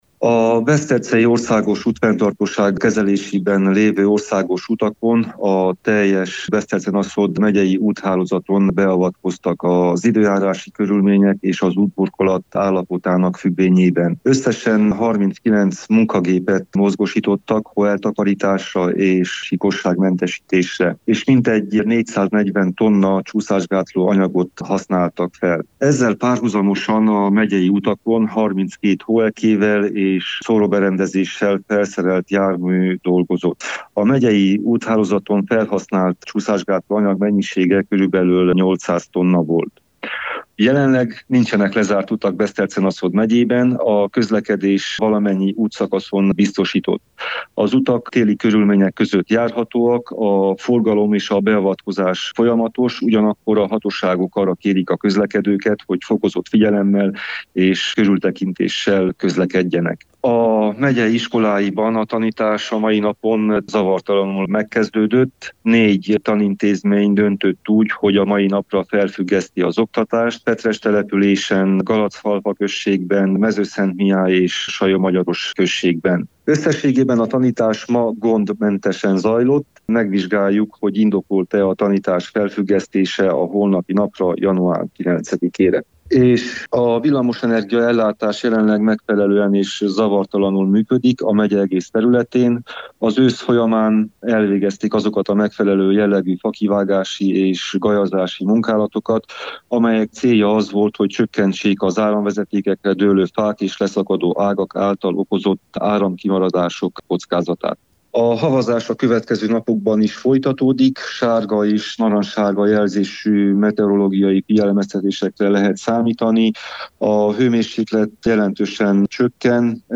Décsei Attila Beszterce-Naszód megyei alprefektus számol be a további részletekről.